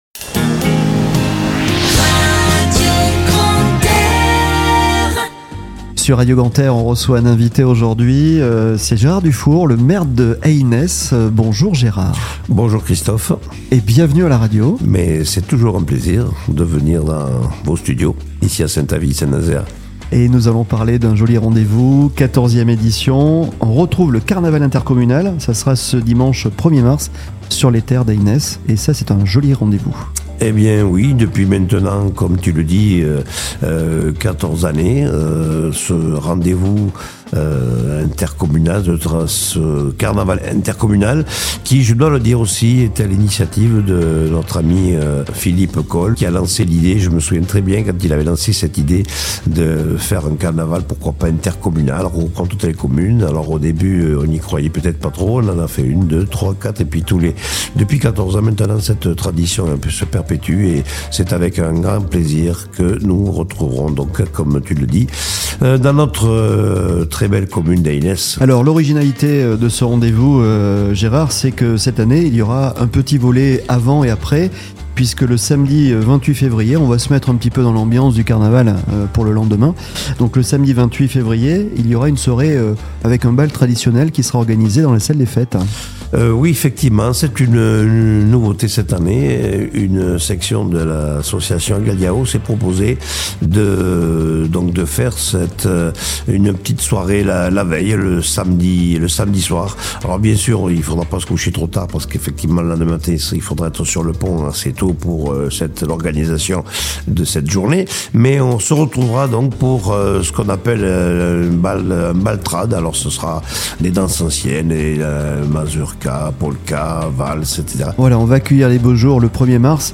Itw Gérard DUFOUR 14ème édition du carnaval inter communal à EYNESSE
On revient sur l’évènement avec Gérard Dufour le maire d'Eynesse sur Radio Grand "R", reportage à écouter en podcast ici ????